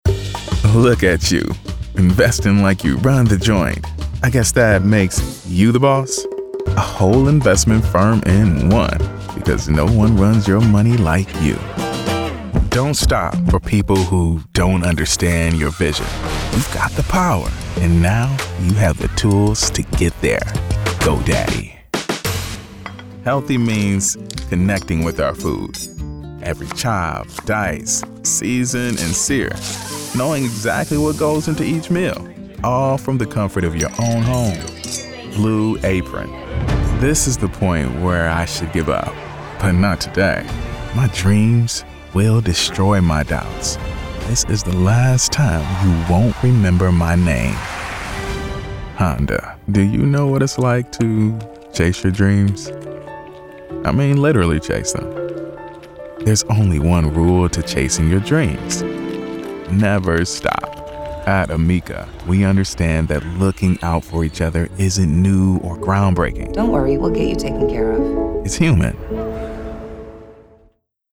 Demonstração Comercial
Microfone: Sennheiser MKH-416
Meia-idade
BarítonoProfundoBaixo